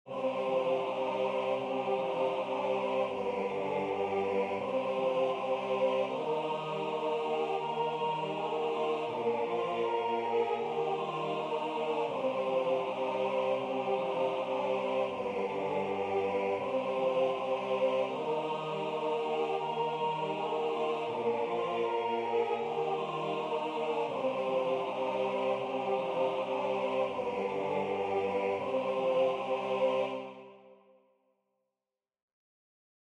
Czech folk song